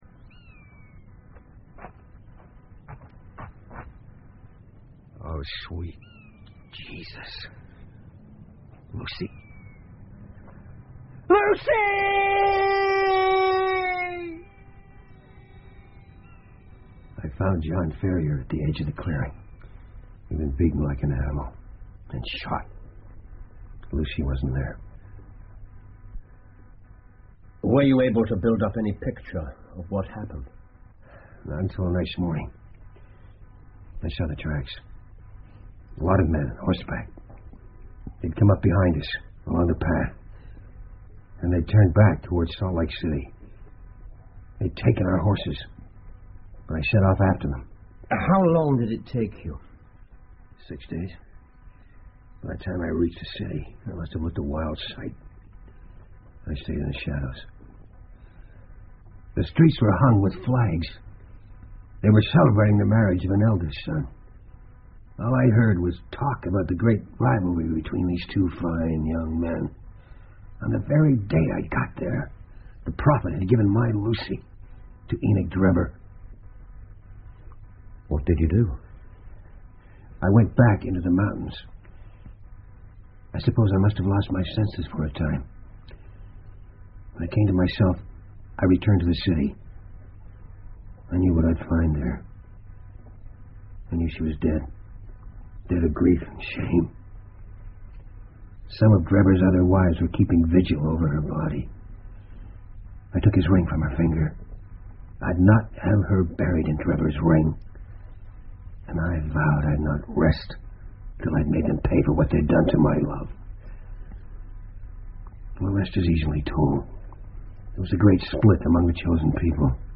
福尔摩斯广播剧 A Study In Scarlet 血字的研究 20 听力文件下载—在线英语听力室